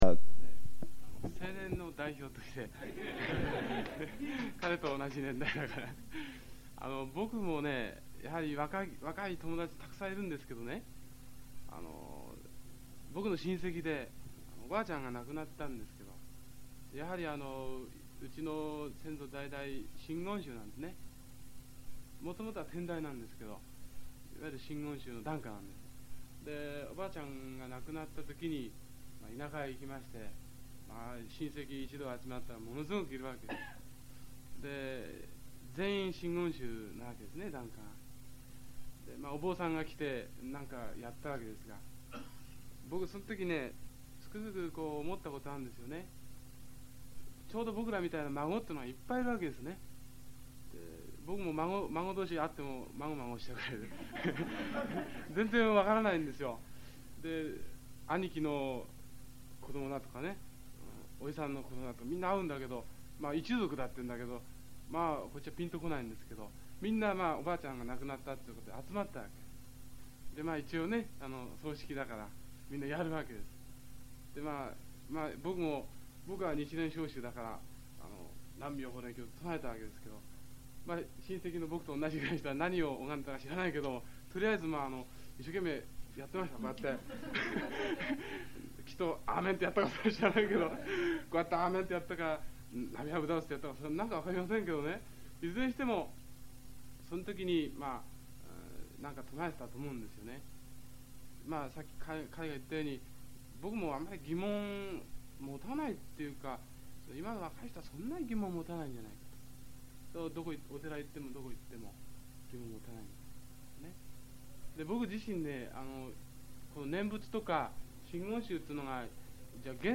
セミナー発言